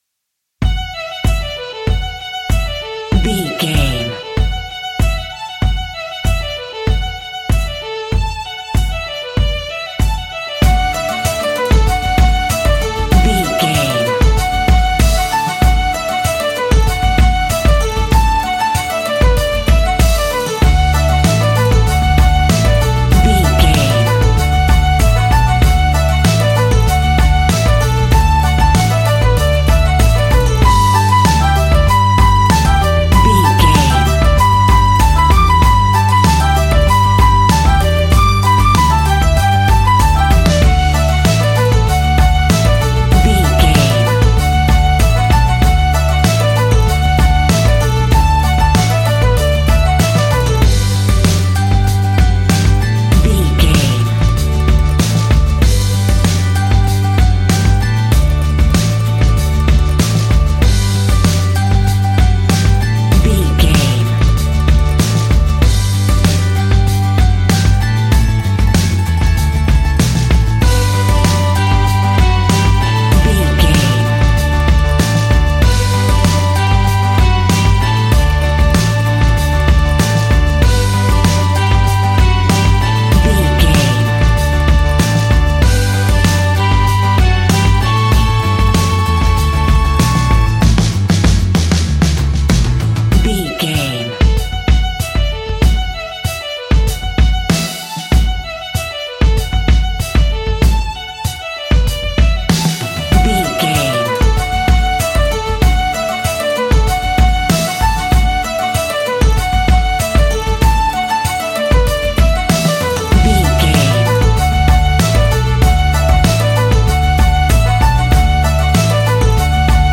Uplifting
Ionian/Major
D
earthy
rural
acoustic guitar
mandolin
ukulele
lapsteel
drums
double bass
accordion